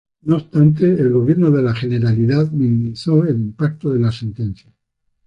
im‧pac‧to
/imˈpaɡto/